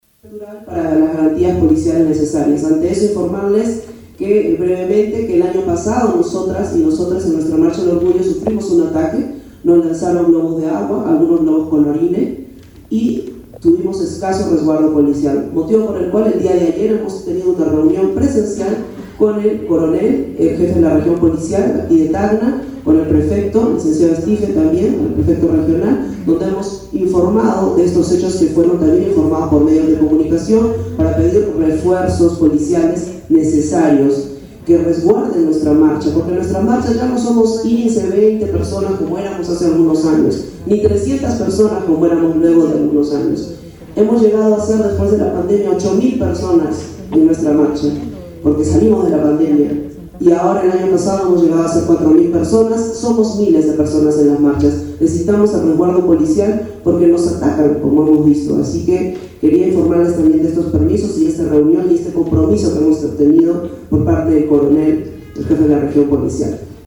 CONFERENCIA.mp3